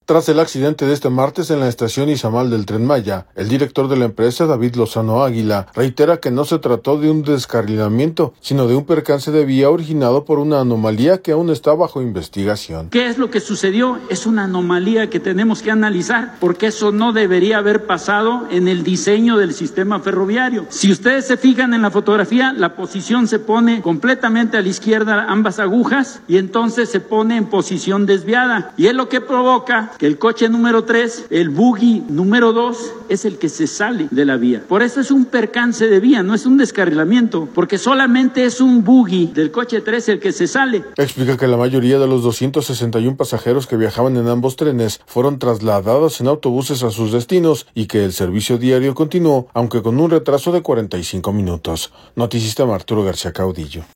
No fue un descarrilamiento sino un percance de vía dice director del Tren Maya sobre el accidente de ayer